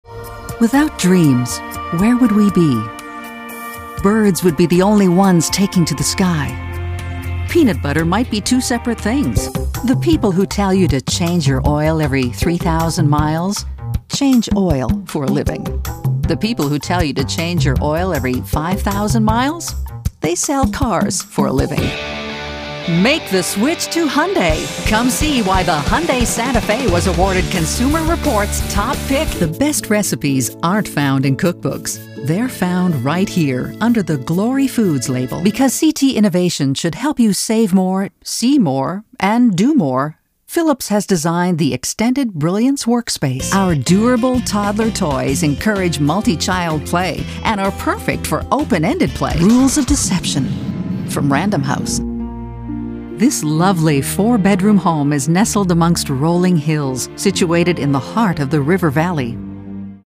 Smooth, rich female voice.
englisch (us)
Sprechprobe: Werbung (Muttersprache):